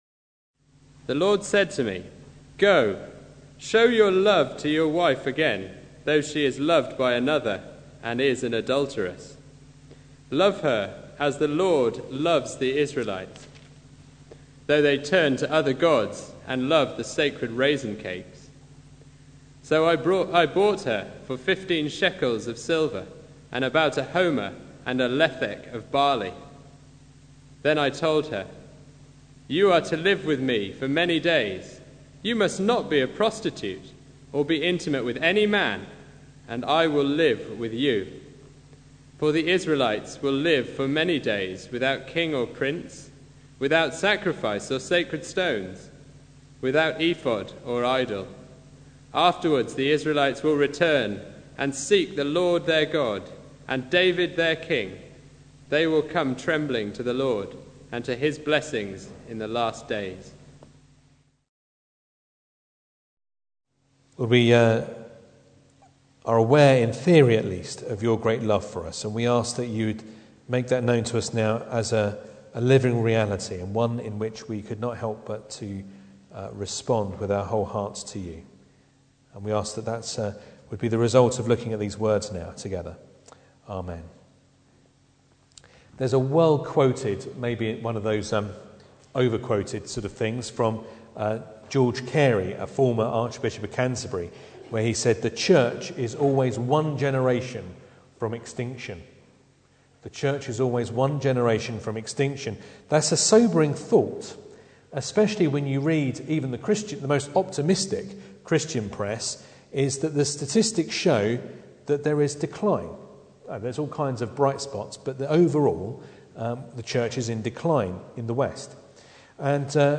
Hosea 3 Service Type: Sunday Evening Bible Text